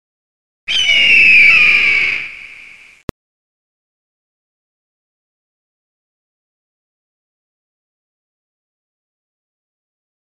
جلوه های صوتی
دانلود صدای عقاب 1 از ساعد نیوز با لینک مستقیم و کیفیت بالا
برچسب: دانلود آهنگ های افکت صوتی انسان و موجودات زنده دانلود آلبوم صدای عقاب در اوج آسمان از افکت صوتی انسان و موجودات زنده